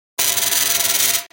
دانلود آهنگ ربات 19 از افکت صوتی اشیاء
جلوه های صوتی
دانلود صدای ربات 19 از ساعد نیوز با لینک مستقیم و کیفیت بالا